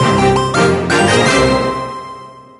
trophy_rank_up_01v2.ogg